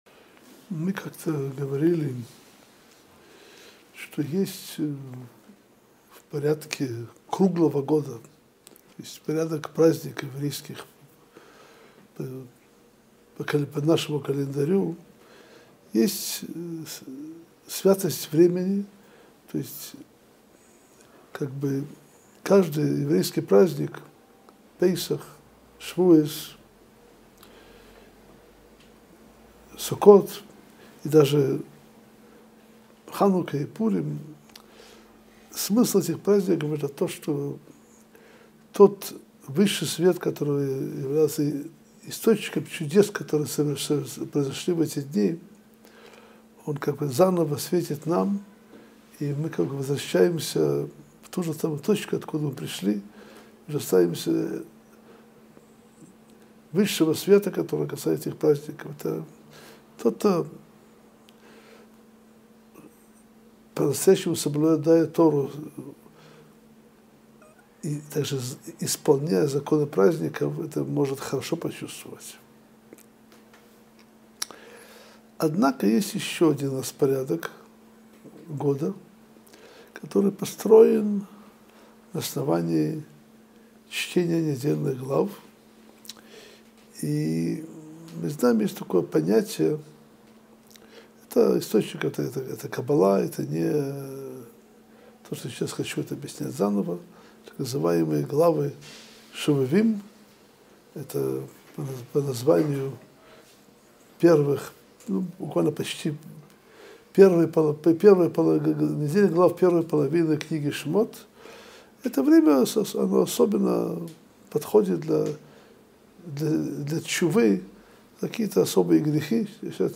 Содержание урока: В чем смысл еврейских праздников? В чем смысл празднования 15 Ава? Почему Хазон Ишу было тяжело учиться в каникулы?